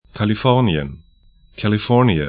Kalifornien kali'fɔrnĭən